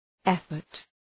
Προφορά
{‘efərt}